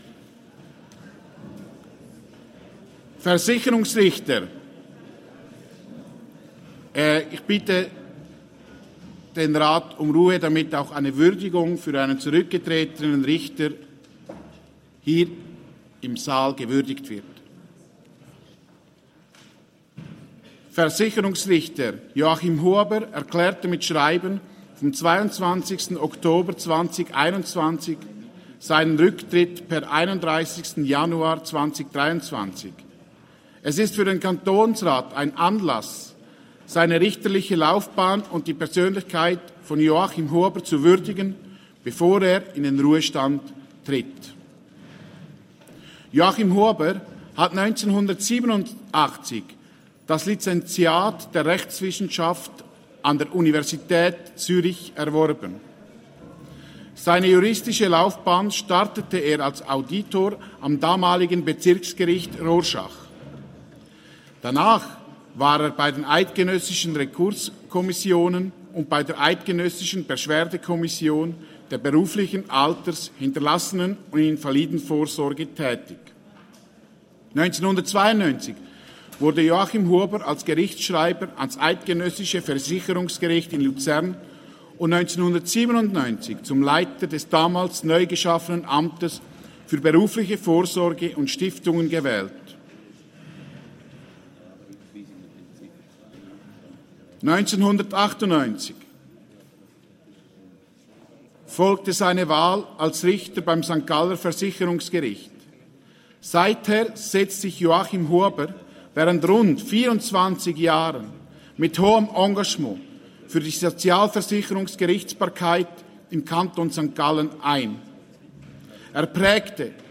Session des Kantonsrates vom 19. bis 21. September 2022